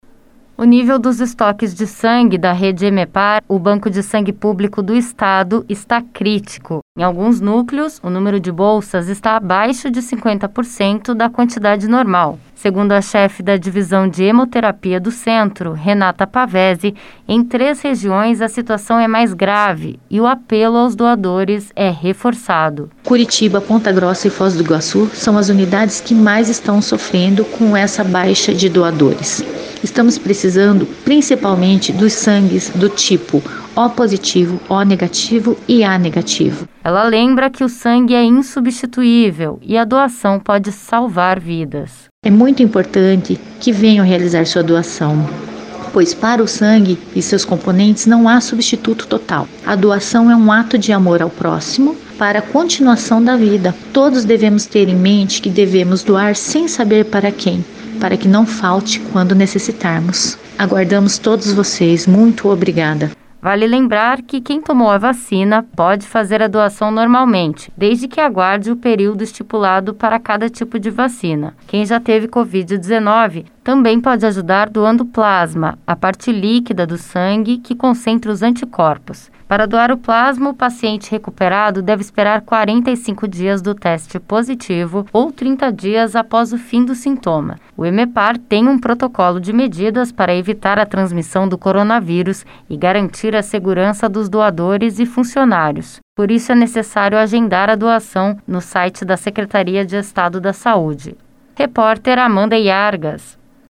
Em mais uma reportagem da Série Vale Muito, saiba como fazer a sua doação de sangue e ajudar a normalizar os estoques do Hemepar. O pedido é urgente porque essa ação de solidariedade pode salvar vidas.